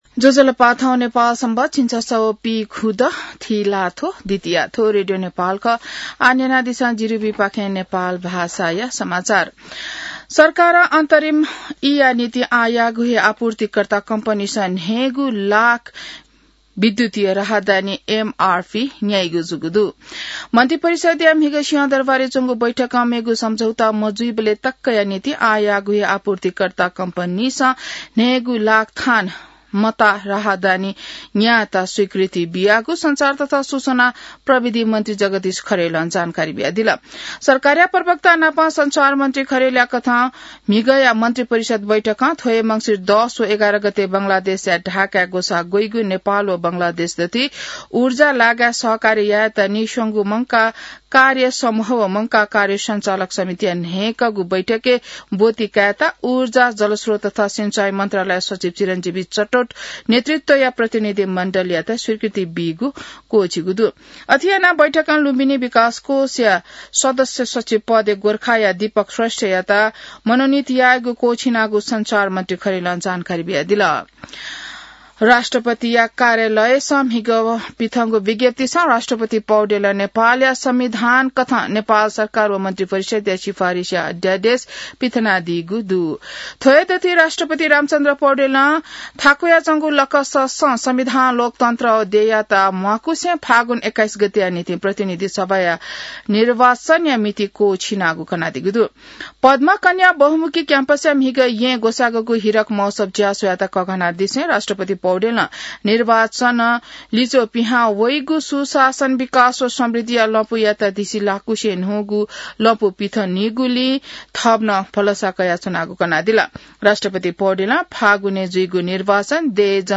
नेपाल भाषामा समाचार : ६ मंसिर , २०८२